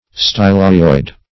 Search Result for " stylohyoid" : The Collaborative International Dictionary of English v.0.48: Stylohyoid \Sty`lo*hy"oid\, a. (Anat.) Of or pertaining to the styloid process and the hyoid bone.